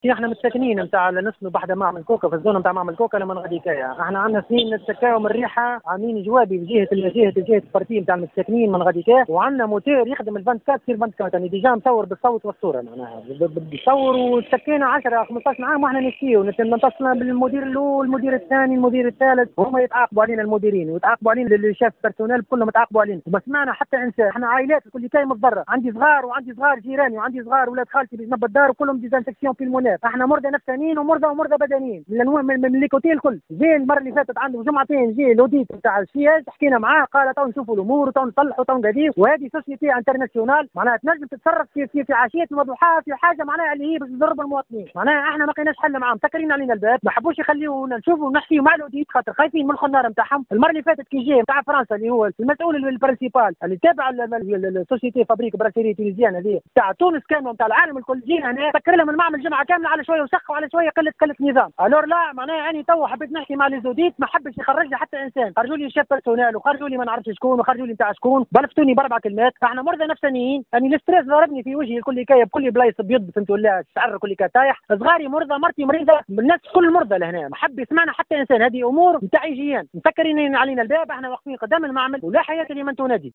رجيش:بسبب انتشار الروائح الكريهة.. الأهالي يحتجون أمام أحد المصانع (تسجيل +فيديو) - Radio MFM